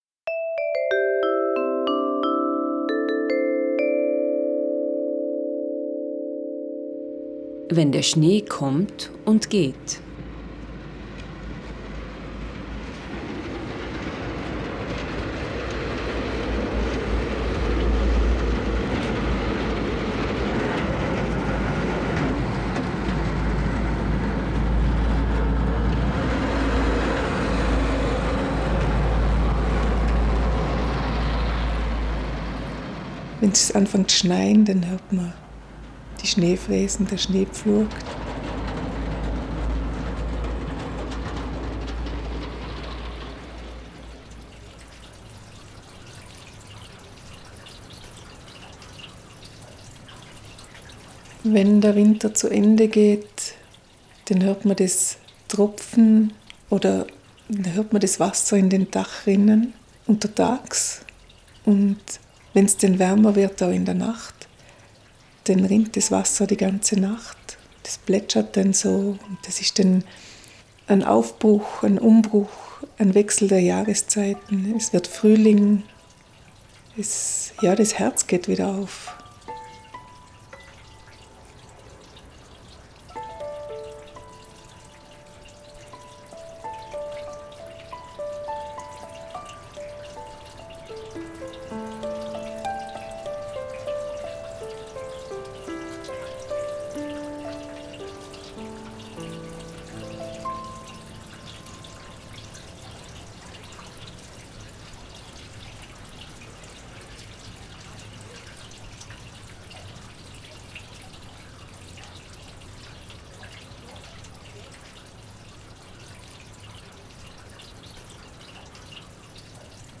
Menschen aus Lech erzählen, welche Geräusche sie in ihrem Alltag begleiten und welche Töne in ihrer Biografie eine Rolle gespielt haben.
Ihre Erzählungen und die damit assoziierten Geräusche verbinden sich mit den eigens dazu komponierten und eingespielten Musikfragmenten zu neun sehr unterschiedlichen Klangreisen in vergangene und gegenwärtige Welten.